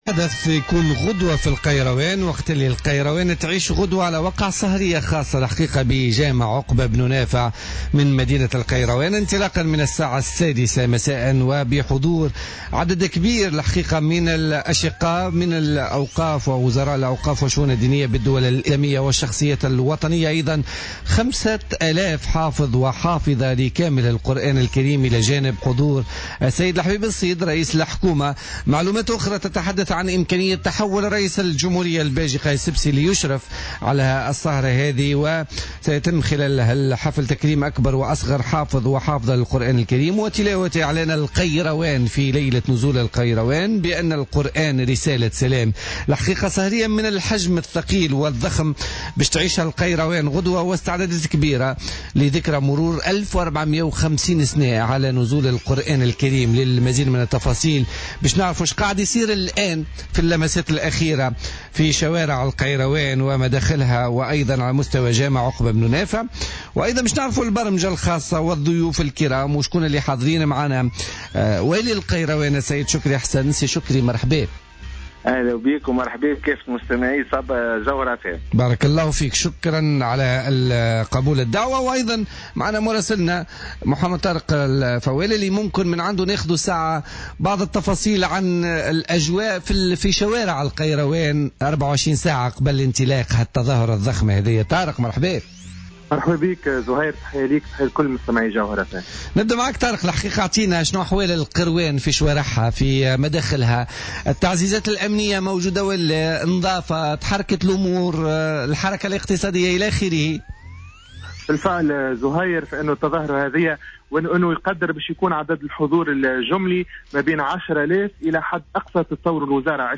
تحدّث والي القيروان، شكري بن حسن عن آخر الاستعدادات لإحياء ذكرى مرور 1450 سنة على نزول القرآن الكريم التي ستنتظم غدا الأربعاء بجامع عقبة بن نافع بمدينة القيروان.